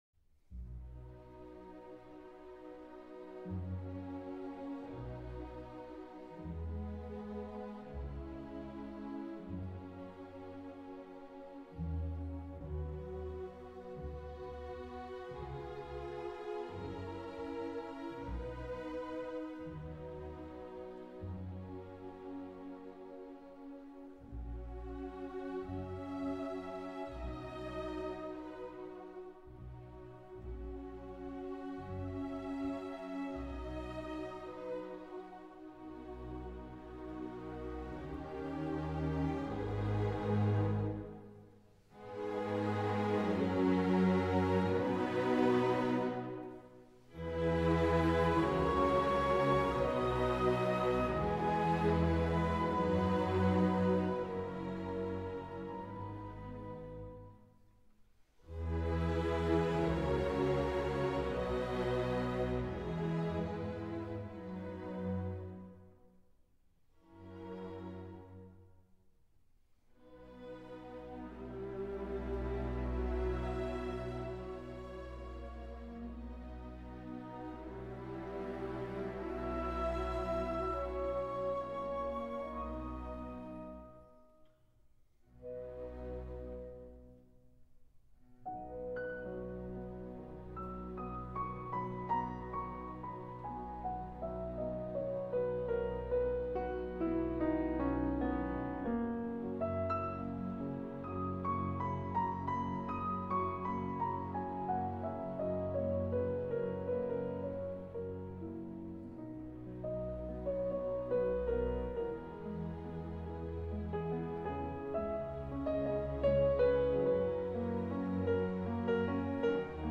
Adagio un poco moto – III. Rondo. Allegro (excerpt) from Piano Concerto No. 5 in E-flat
ii.-adagio-un-poco-moto-iii.-rondo.-allegro-excerpt-from-piano-concerto-no.-5-in-e-flat.mp3